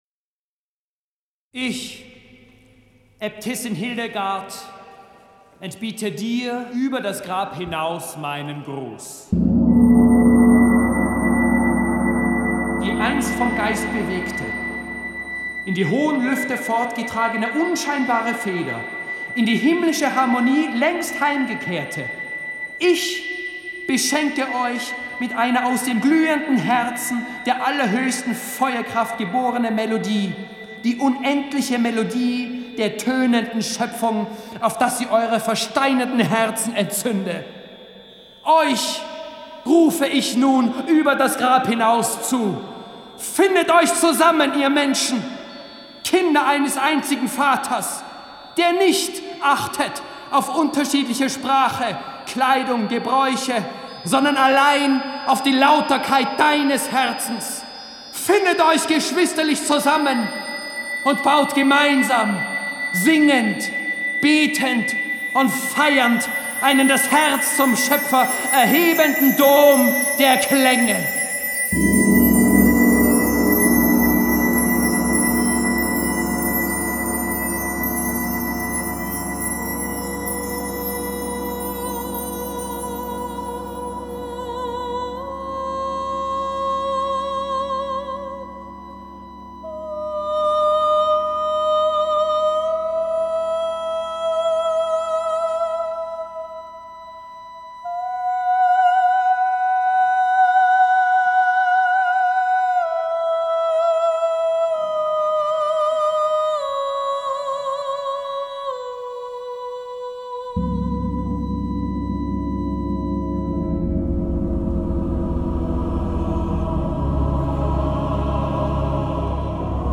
Deutsche Erstaufführung im Münster zu Konstanz